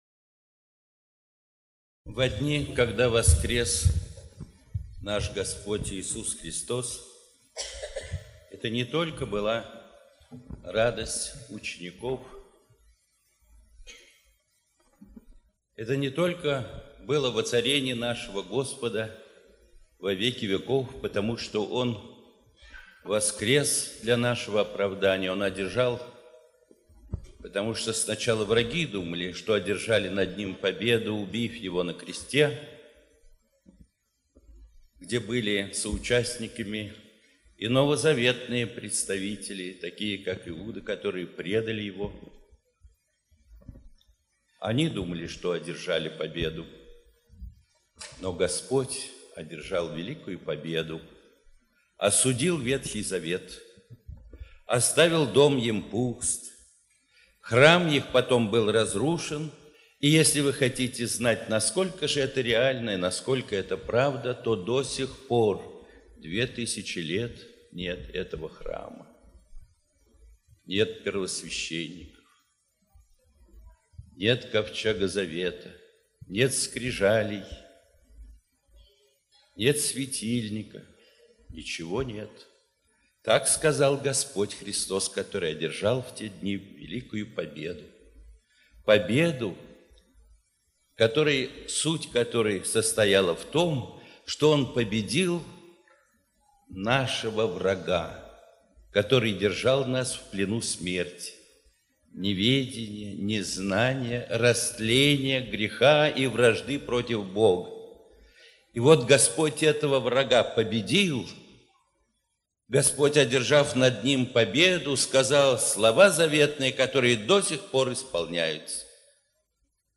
Выйдем к Нему за стан (проповеди